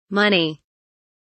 money kelimesinin anlamı, resimli anlatımı ve sesli okunuşu